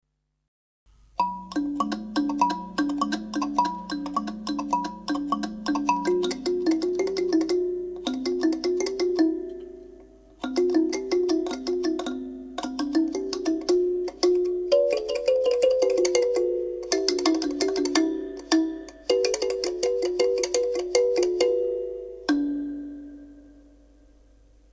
大いなる父カリンバ　母カリンバ　子カリンバ　孫カリンバ
本体の大きさで音の響きがかわります　指ではじいて演奏しますが　大きなものはやわらかなマレットでたたいても良い音がします